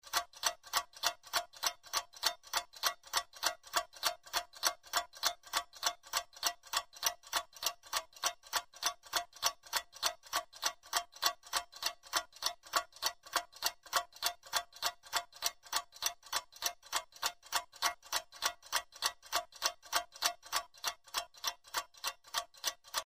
Звуки тиканья
Звук больших настенных часов